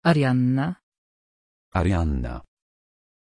Pronuncia di Arianna
pronunciation-arianna-pl.mp3